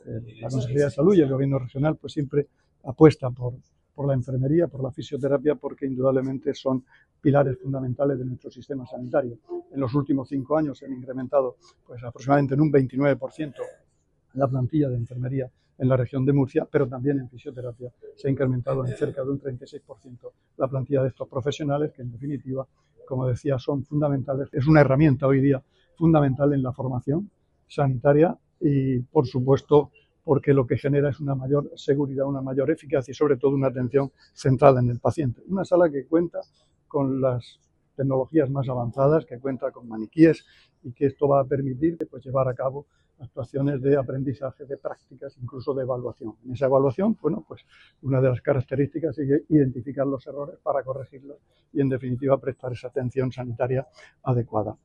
El consejero de Salud, Juan José Pedreño, ofreció hoy estos datos en la inauguración de una Sala de Simulación Clínica en la sede del Sindicato de Enfermería Satse, donde destacó el valor de espacios de formación como este para ganar en innovación y una asistencia cada vez más segura, eficaz para los pacientes.
Sonido/ Declaraciones del consejero de Salud, Juan José Pedreño, sobre el aumento de la plantilla de Enfermería y Fisioterapia en el SMS y lo que supone la simulación en la formación de estos profesionales.